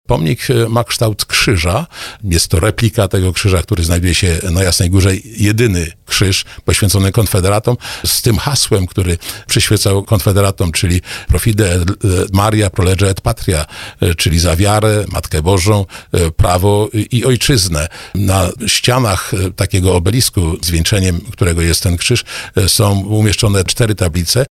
W porannej rozmowie Słowo za Słowo mówił, że pomnik jest dopracowany w każdym szczególe.